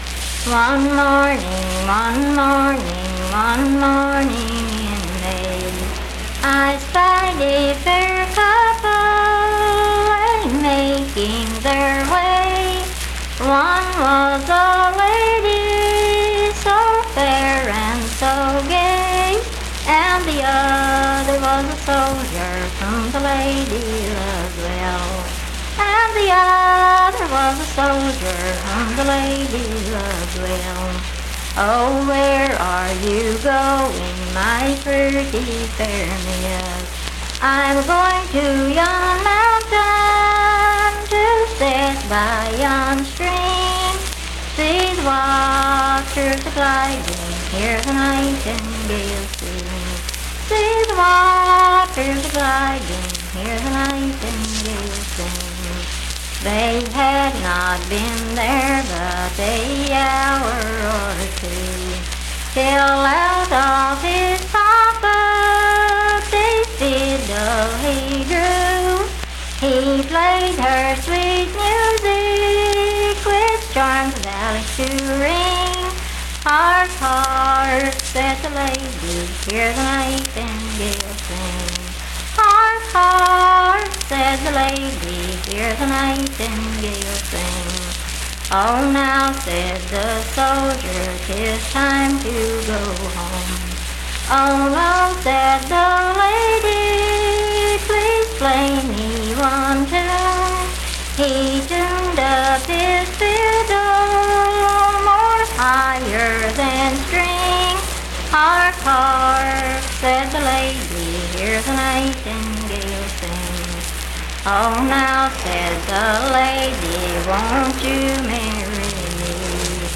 Unaccompanied vocal music
Voice (sung)
Roane County (W. Va.), Spencer (W. Va.)